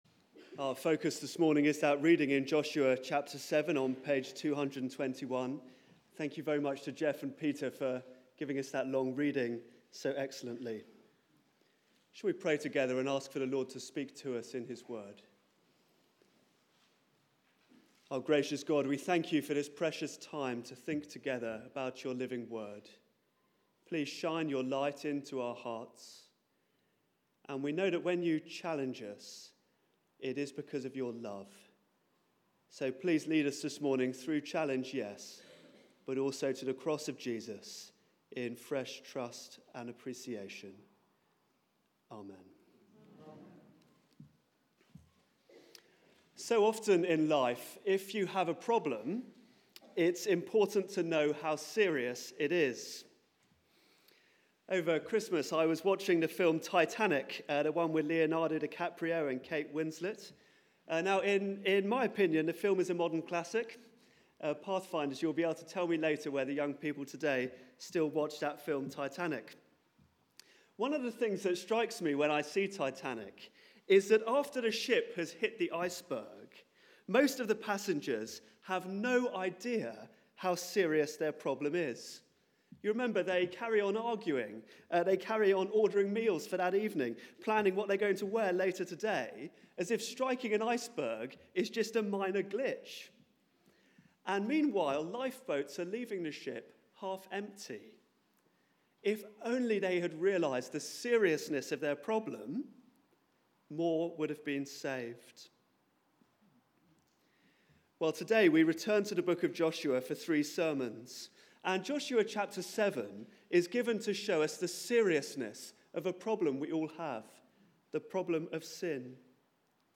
Passage: Joshua 7 Series: Great Is Thy Faithfulness Theme: The seriousness of sin Sermon (11:00 Service)